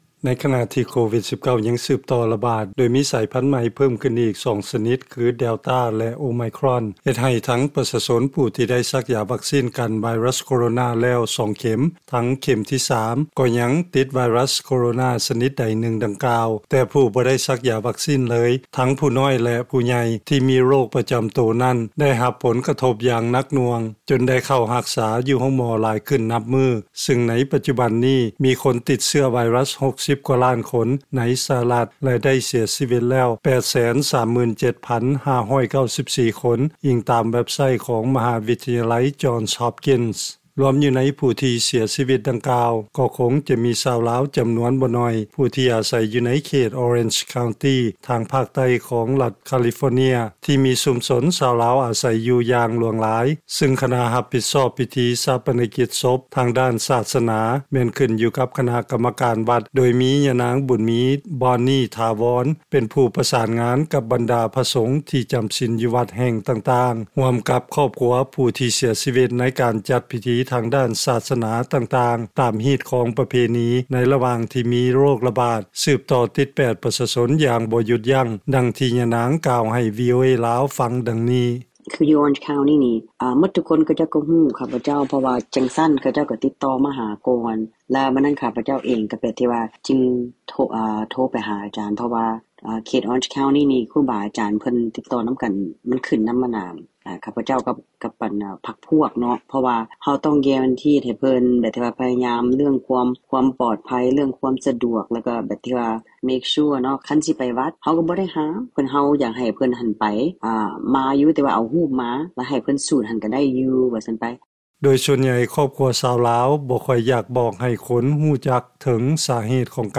ເຊີນຟັງລາຍງານ ພິທີສົ່ງສະການ ຂອງຊາວລາວ ໃນເຂດອໍເຣັນຈ໌ ຄາວຕີ ໄດ້ຖືກປັບໃຫ້ເໝາະສົມ ກັບຂໍ້ບັງຄັບ ຍ້ອນໄວຣັສໂຄວິດ